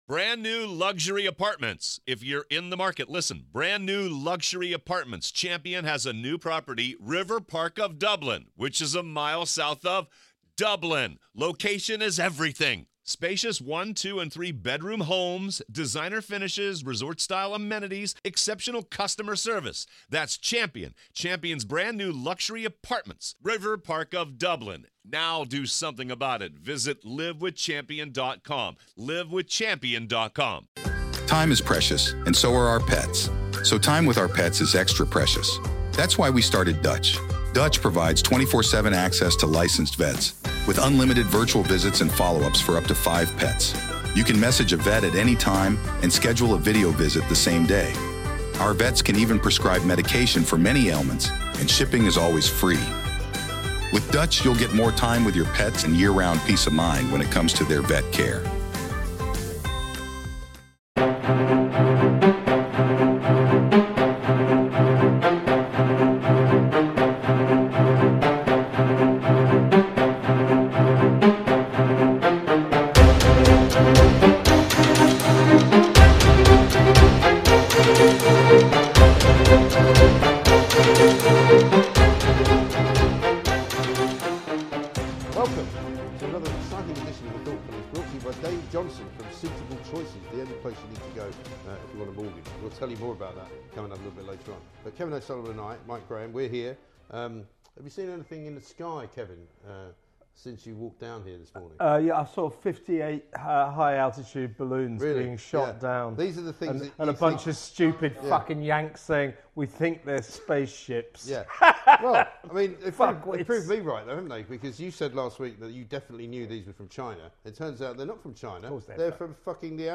The lads are back with another rage filled deep dive into the past weeks top stories, and the very worst...